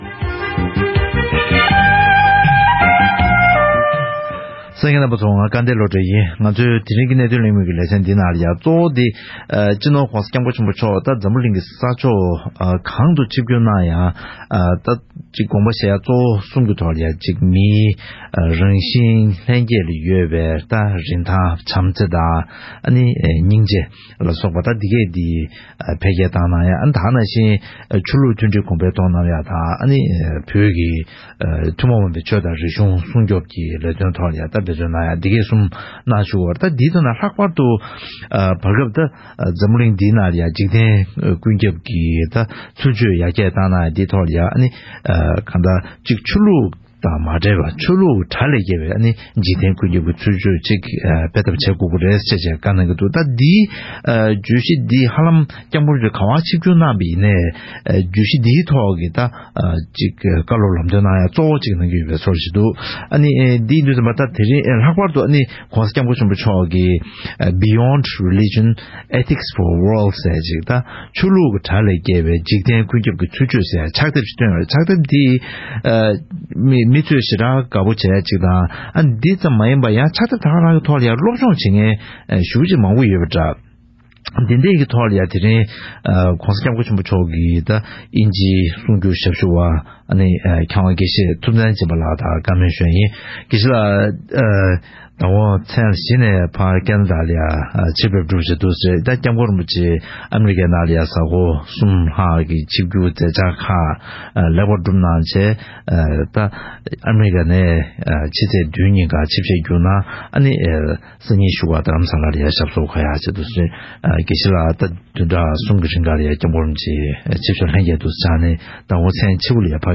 ༸གོང་ས་མཆོག་གི་མཛད་འཆར་སྐོར་གླེང་མོལ།